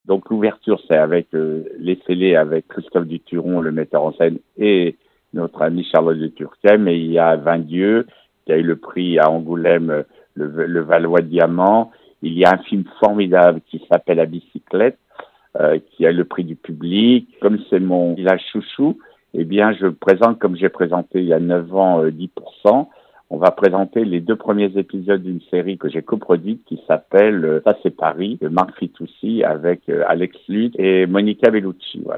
Dominique Besnehard nous parle de cette édition 2024 :